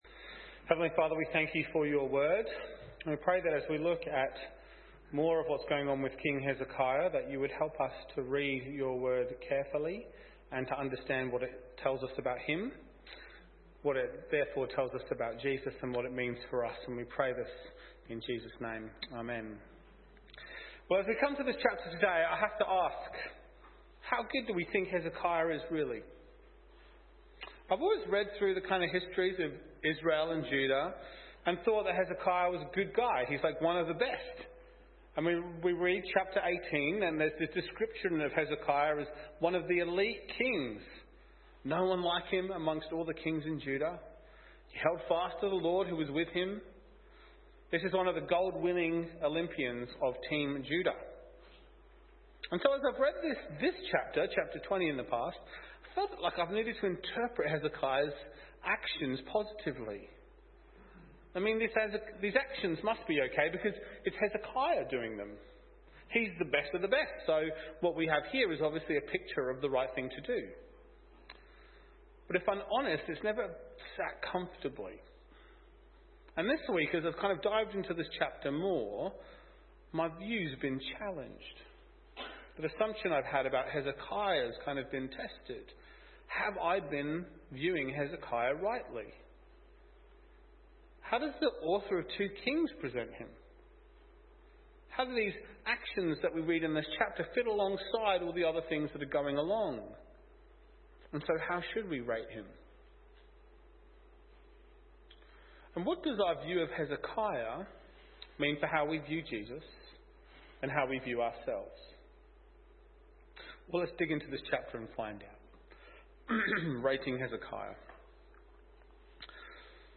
2 Kings | Sermon Books |